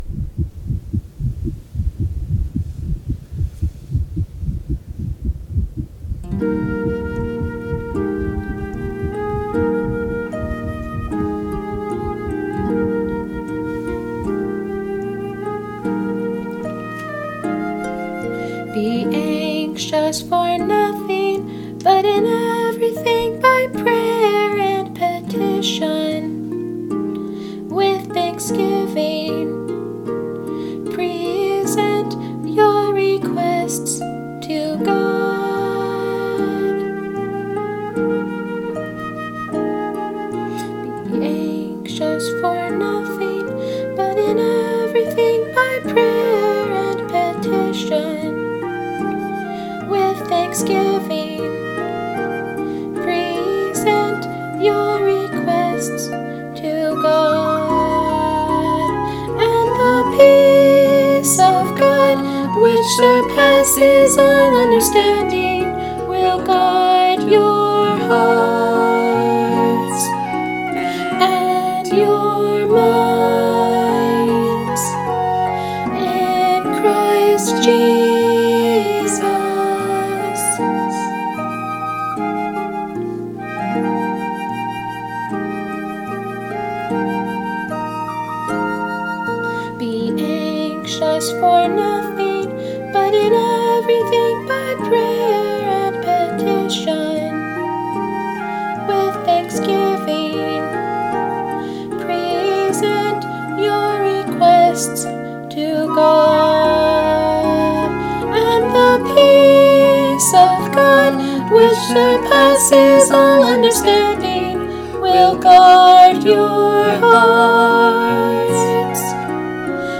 cover song